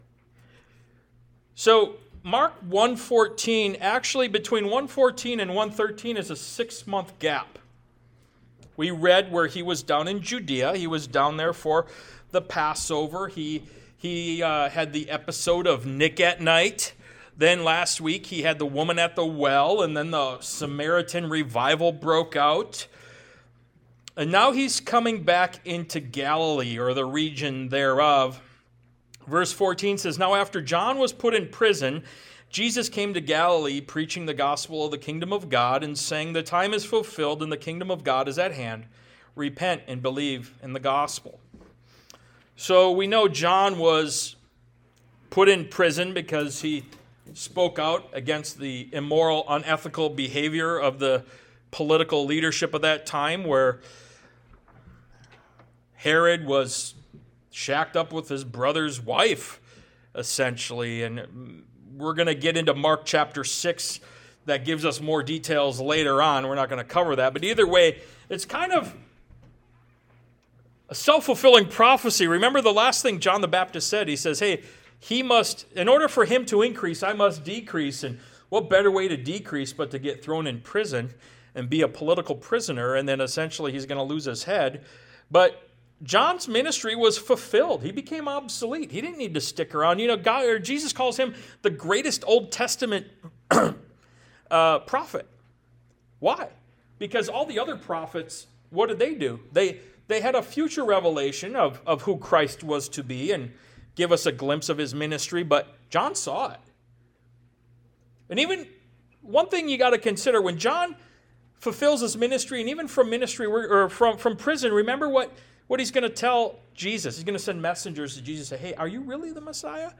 Ministry of Jesus Service Type: Sunday Morning « “Are You Thirsty?”